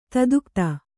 ♪ tadukta